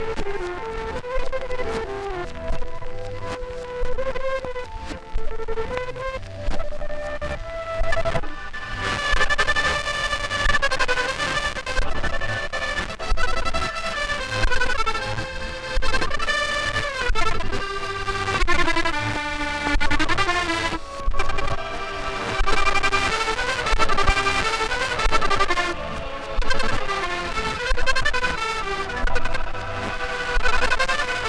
le test là c'est un 45trs sur lequel jai mis une couche de colle PVA, ça me fait un disque mou tout crasseux